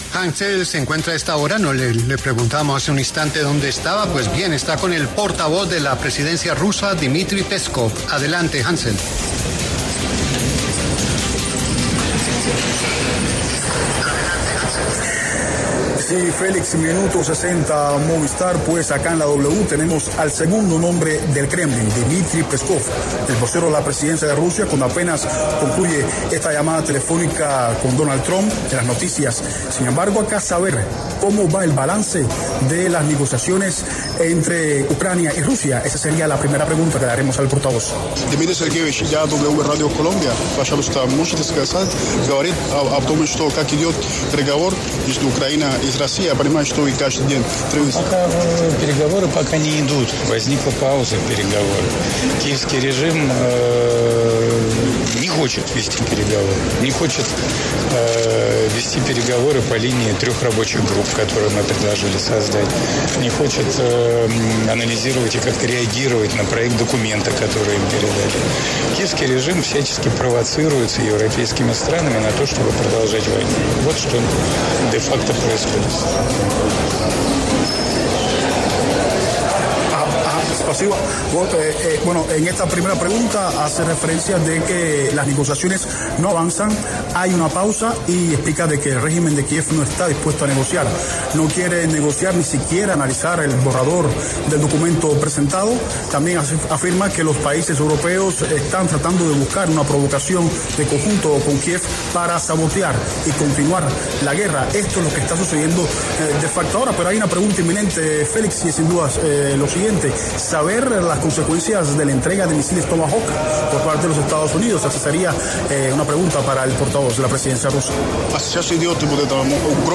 Sobre el tema, La W conversó con Dmitri Peskov, secretario de Prensa y subjefe de la Oficina Ejecutiva del presidente de Rusia, quien se pronunció sobre las propuestas que tiene Trump para el conflicto.